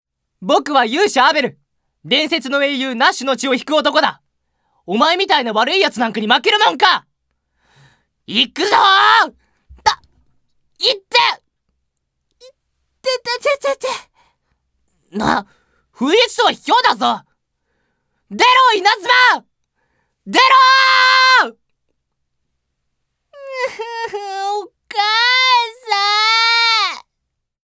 高音出ます。
ボイスサンプル、その他
セリフ３